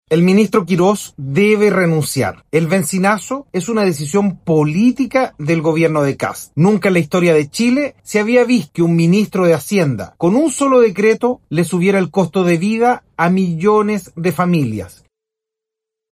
Más duro fue el diputado socialista Daniel Manouchehri, quien planteó derechamente la salida del ministro de Hacienda.